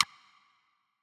jetson rim.wav